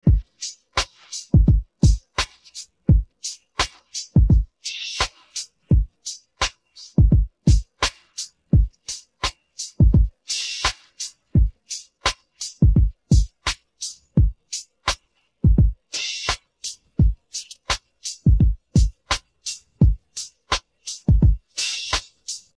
Rap Song Loop